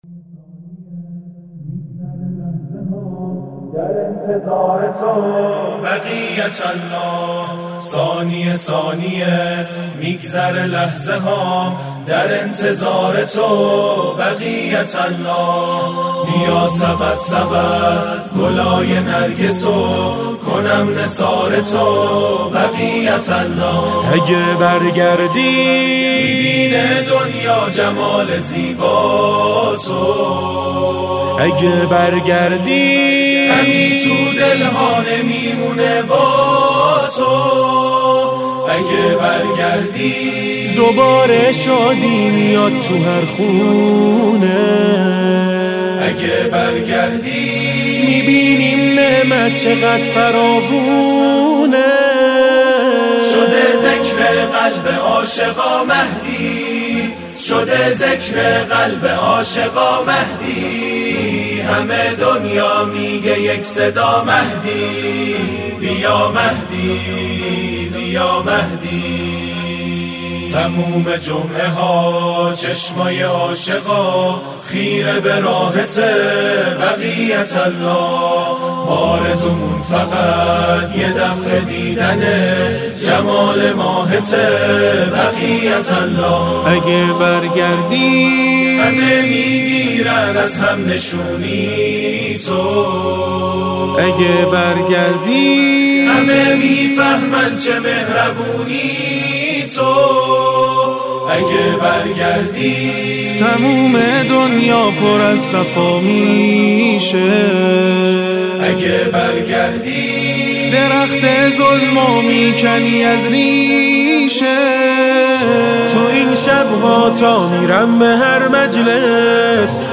ویژه نیمه شعبان المعظم